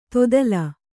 ♪ todala